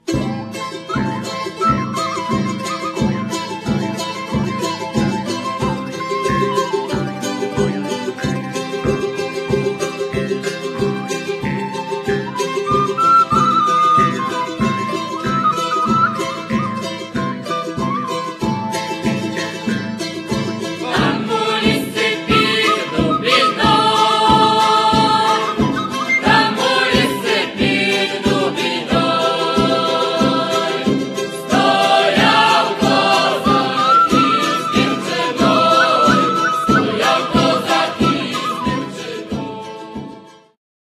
akordeon, drumle, ¶piew accordion, Jew's harps, vocals
gitara, skrzypce, ¶piew guitar, violin, vocals
instrumenty perkusyjne percussion instruments